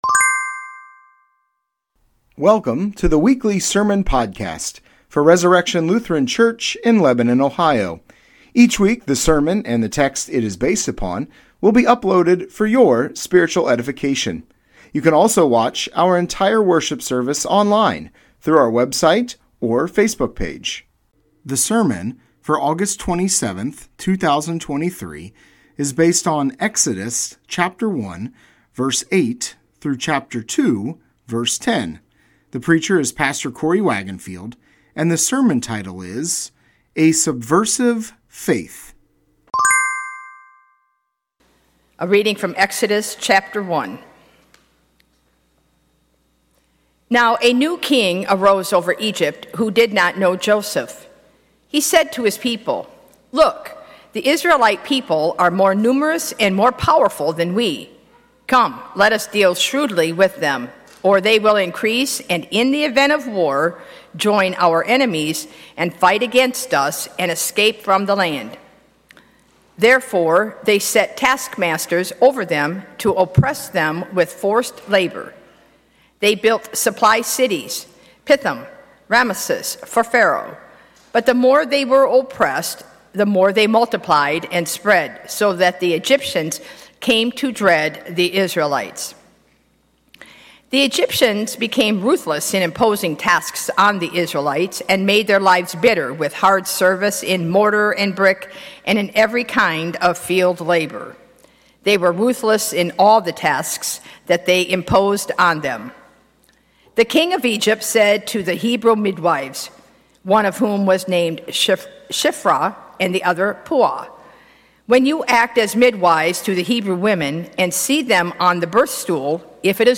Sermon Podcast Resurrection Lutheran Church - Lebanon, Ohio August 27, 2023 - "A Subversive Faith"